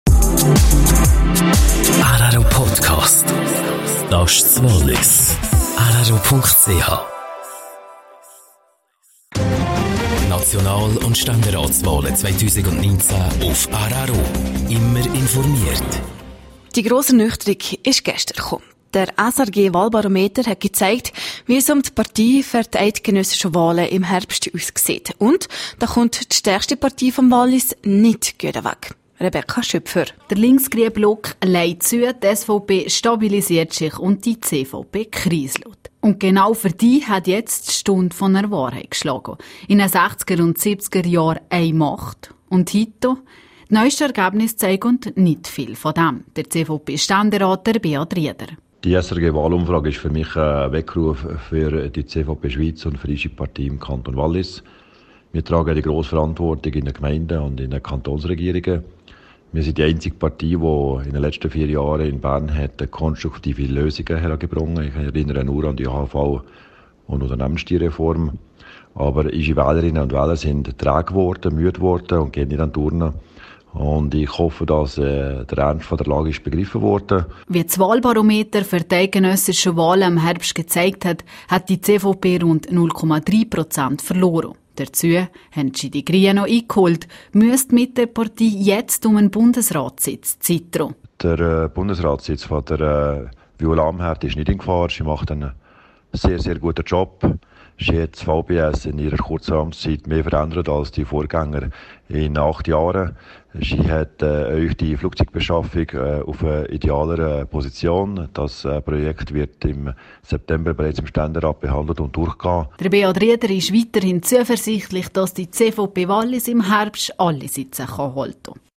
Ausserdem ist Rieder zuversichtlich, dass die CVP Wallis im Herbst ihre Sitze halten kann./sr CVP-Ständerat Beat Rieder zum SRG-Wahlbarometer (Quelle: rro)